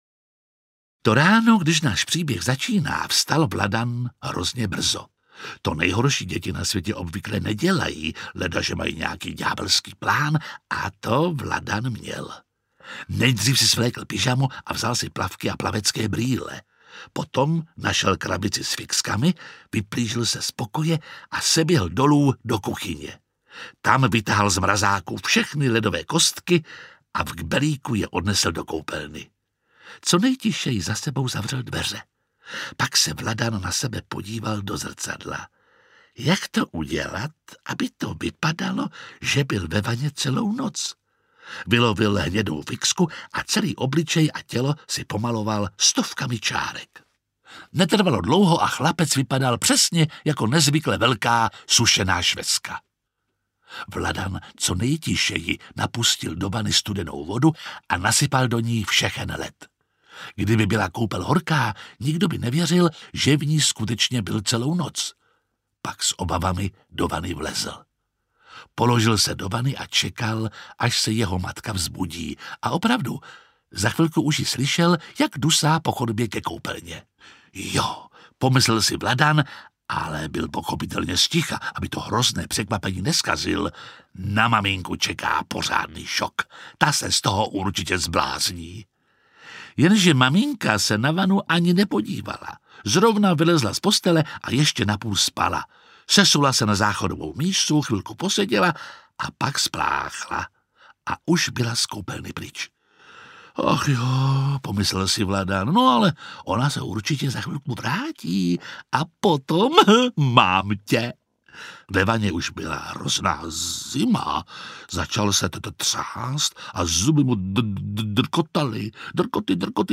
Nejhorší děti na světě 3 audiokniha
Ukázka z knihy
| Čte Jiří Lábus.
• InterpretJiří Lábus